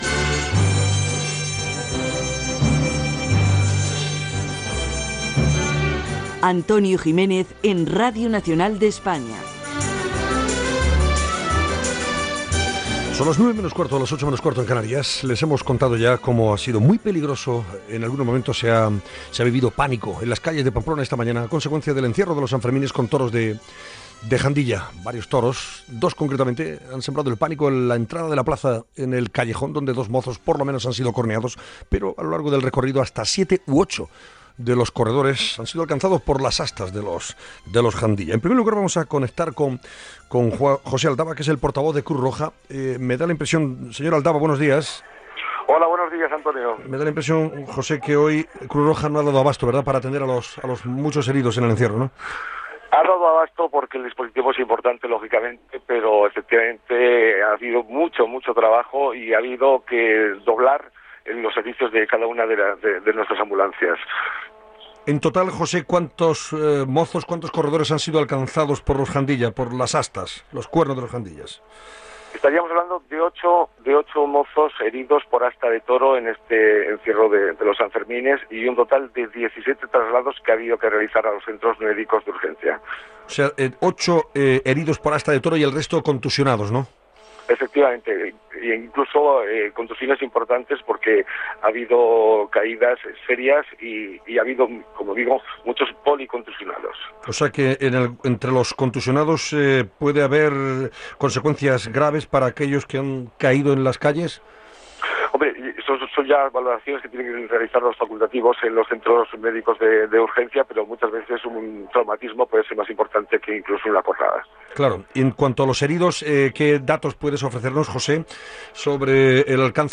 Indicatiu del programa informació, des de Pamplona, dels ferits a l'"encierro" dels braus de Jandilla.
Info-entreteniment